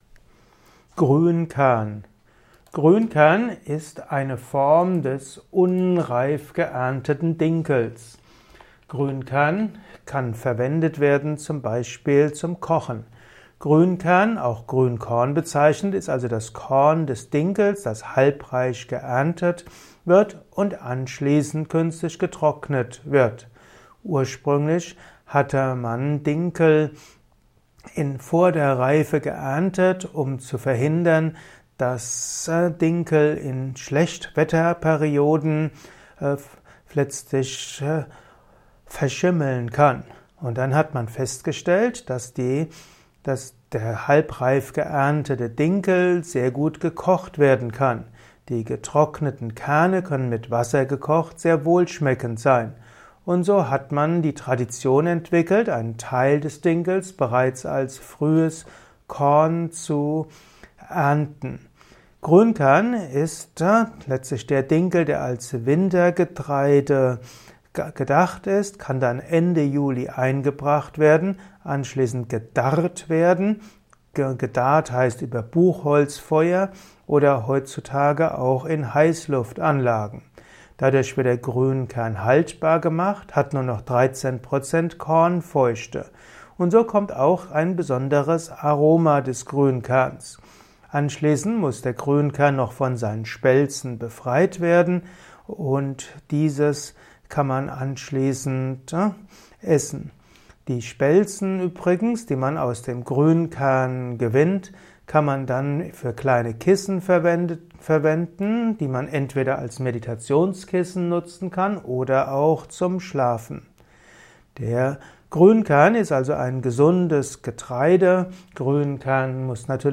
Simple und komplexe Infos zum Thema Grünkern in diesem Kurzvortrag